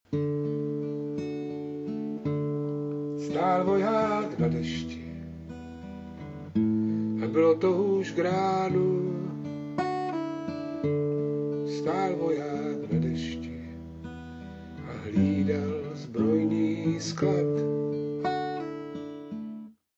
Beru pár úvodních veršů básně a s kytarou v ruce zkouším vyhmátnout náladu celé básně.
Co takhle zkusit sladkým a vzletným prozpěvováním vytvořit absurdní kontrast k tragické opuštěnosti vojáka na stráži a v třaskavém kontrapunktu slov a melodie